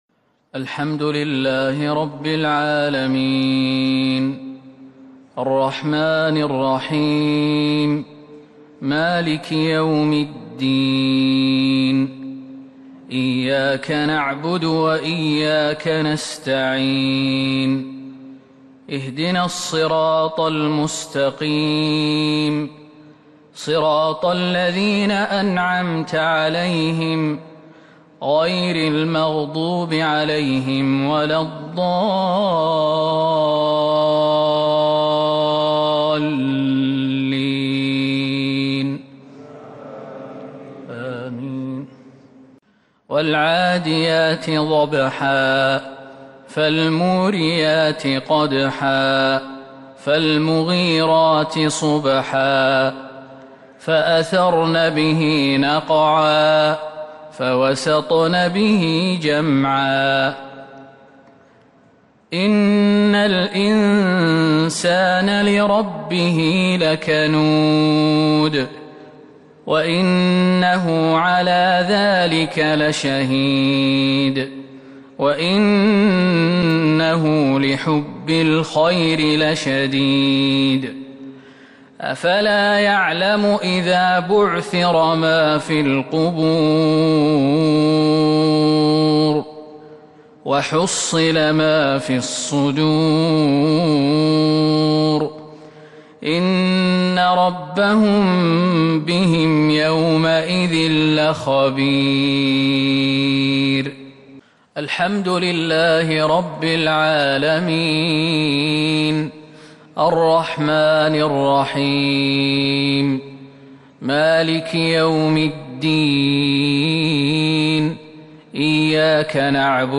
مغرب السبت 3-3-1443هـ سورتي العاديات والإخلاص | Maghrib prayer Surah Al-'Adiyat and Al-Ikhlas 9/10/2021 > 1443 🕌 > الفروض - تلاوات الحرمين